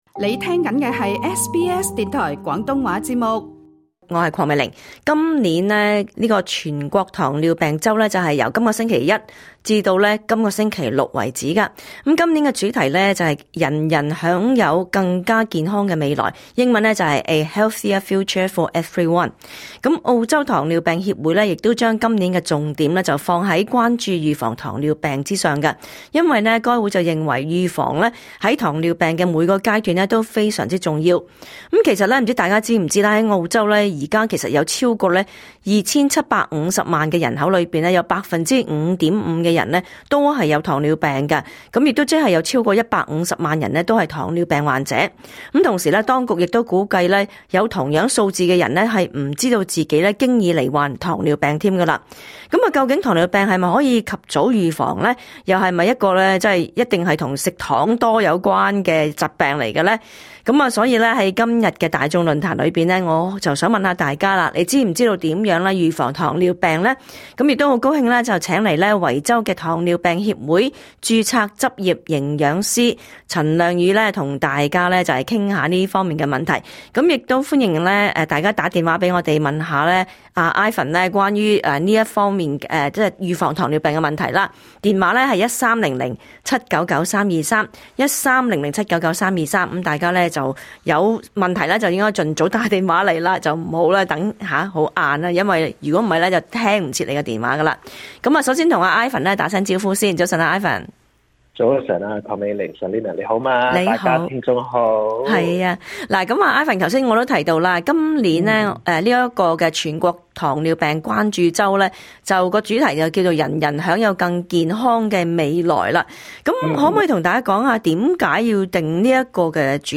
*本節目內嘉賓及聽眾意見並不代表本台立場，而所提供的資訊亦只可以用作參考，個別實際情況需要親自向有關方面查詢為準。